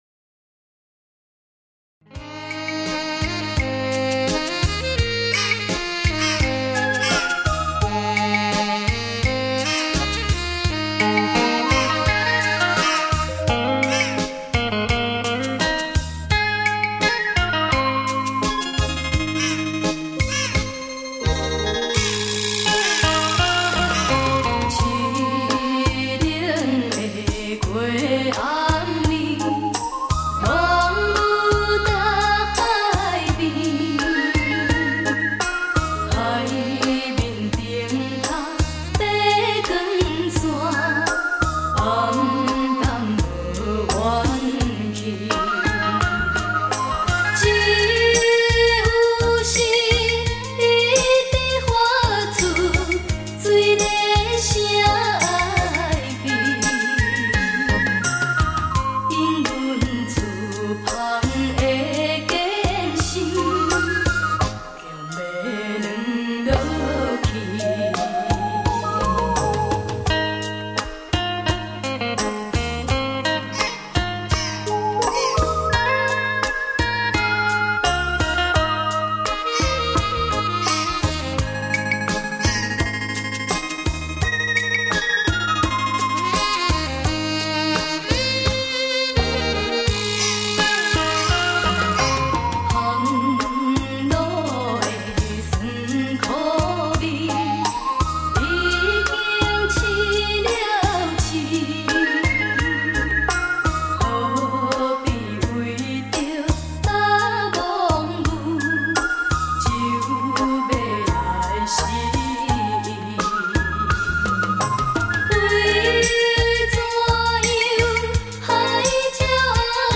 台语CD